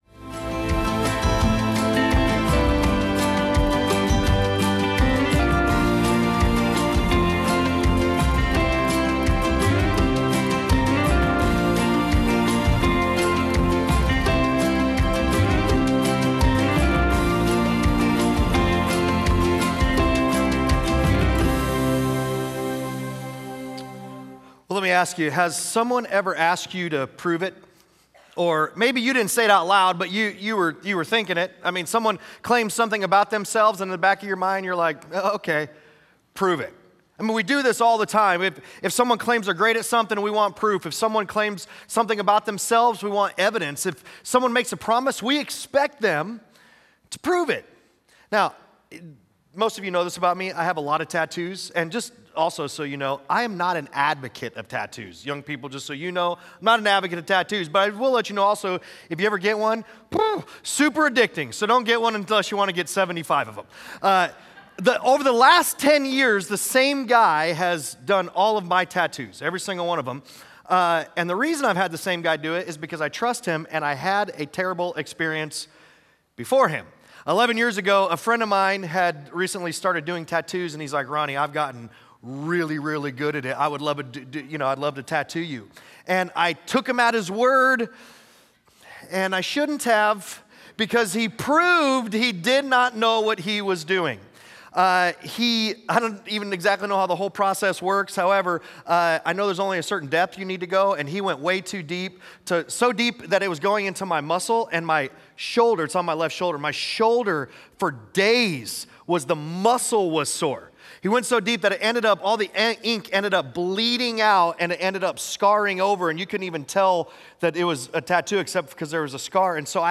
Sunday Sermons FruitFULL, Week 1: "Love" Jan 25 2026 | 00:36:29 1x 00:00 / 00:36:29 Subscribe Share Apple Podcasts Spotify Overcast RSS Feed Share Link Embed